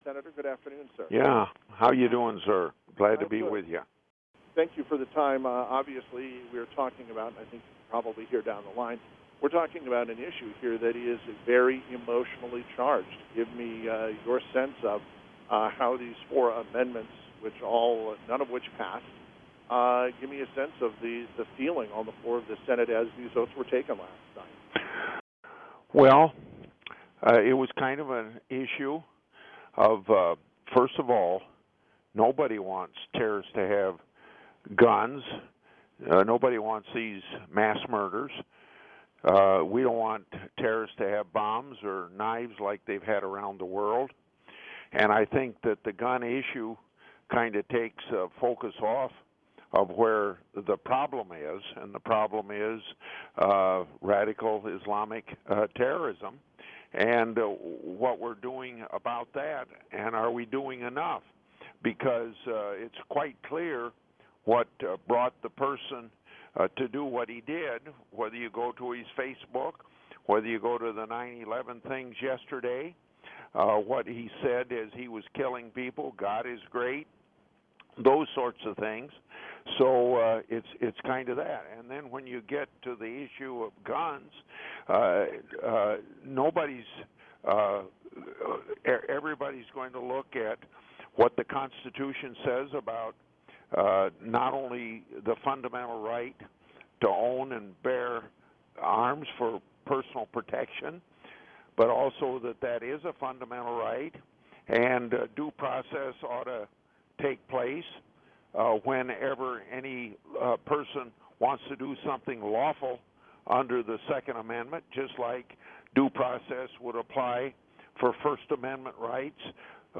Public Affairs Program, 6-21-16, KASI.mp3